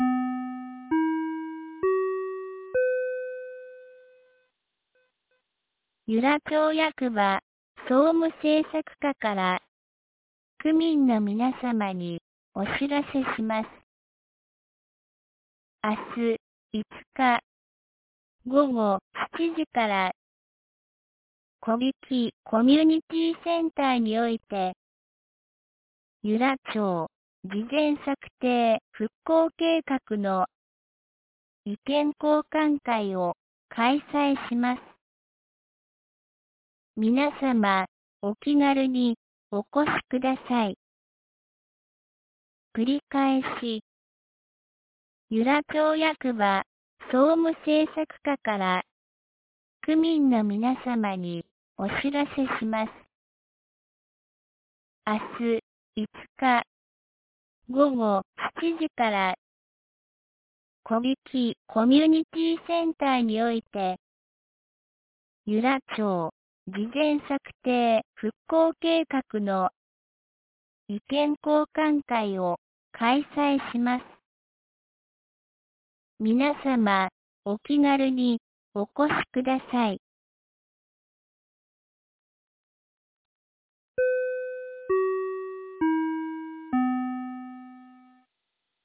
2022年11月04日 17時18分に、由良町から小引地区へ放送がありました。